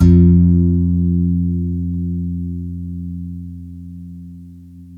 GUITARON 02L.wav